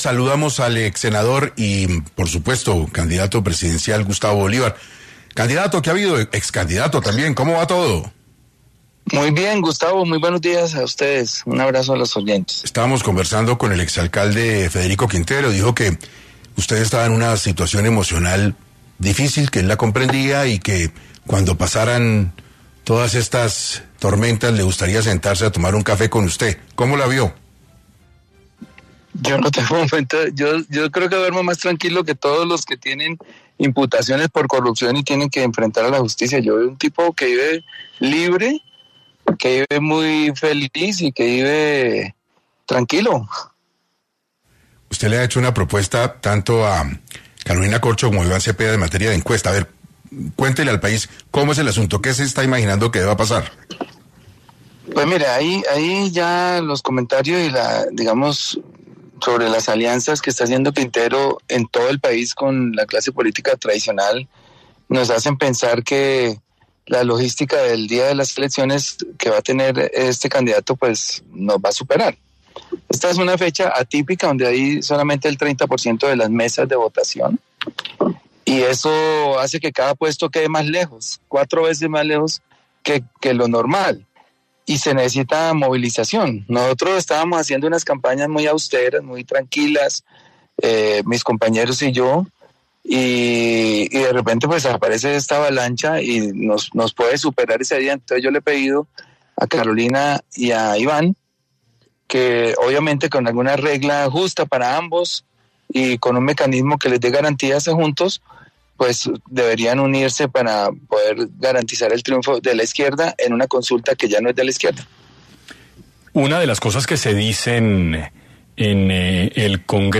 En este contexto, Bolívar pasó por los micrófonos de 6AM para profundizar en estas disputas internas que se desarrollan antes de la consulta.